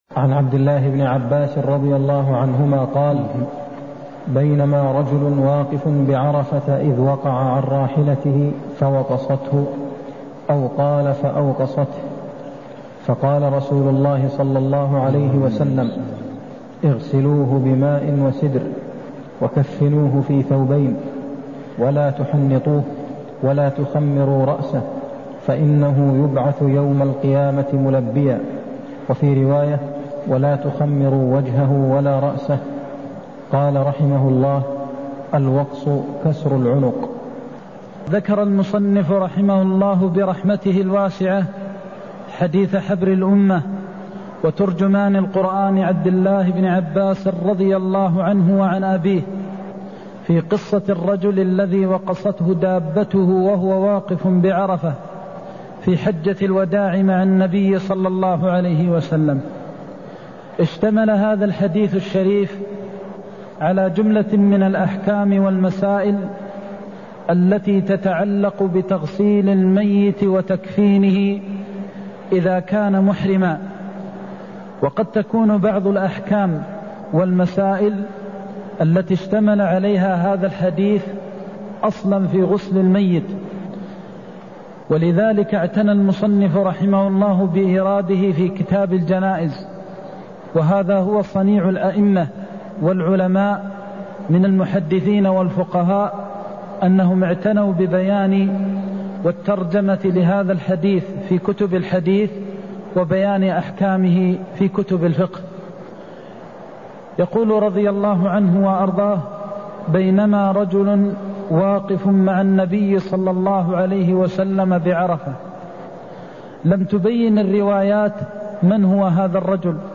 المكان: المسجد النبوي الشيخ: فضيلة الشيخ د. محمد بن محمد المختار فضيلة الشيخ د. محمد بن محمد المختار صفةغسل الميت وتكفينه إذا كان محرماً (154)